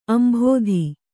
♪ ambhōdhi